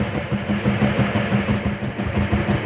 Index of /musicradar/rhythmic-inspiration-samples/90bpm
RI_DelayStack_90-03.wav